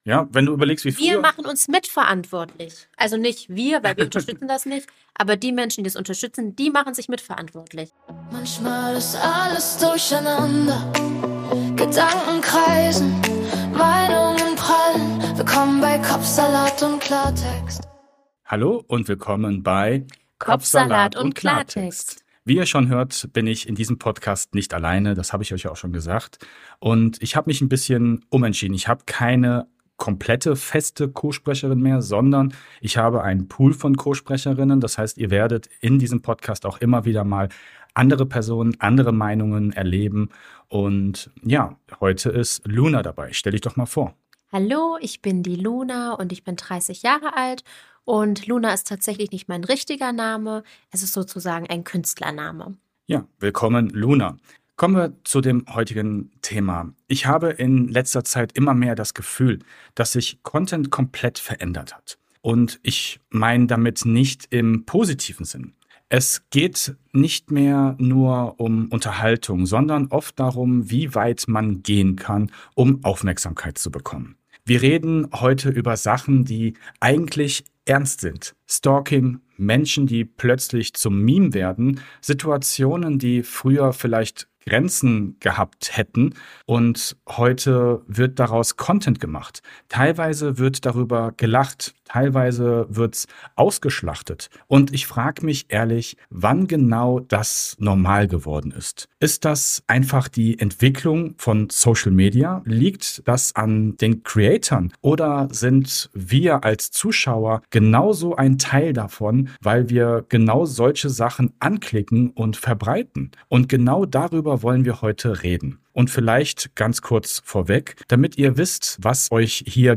Ein ehrliches Gespräch mit unterschiedlichen Meinungen, klaren Worten und der Frage, wo wir als Gesellschaft eigentlich gerade stehen.